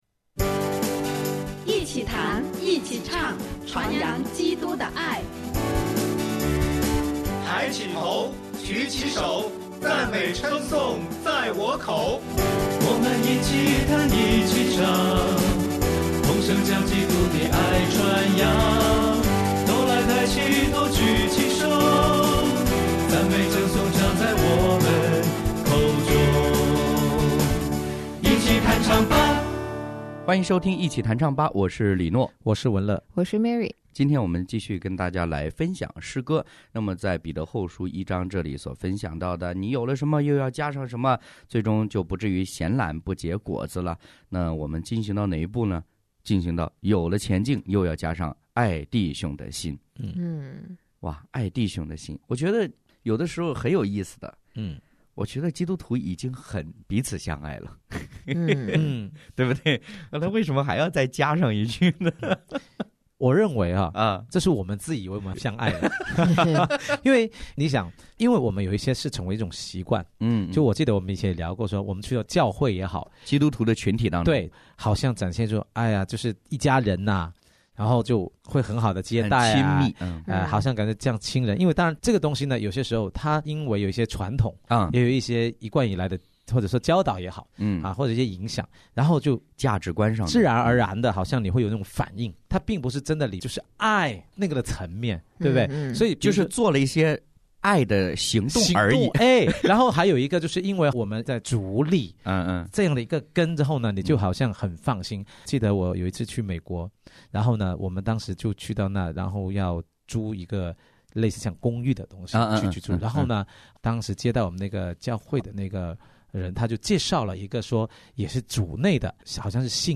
结出果子的品格：爱弟兄的心；诗歌：《同路人》、《爱使我们勇敢》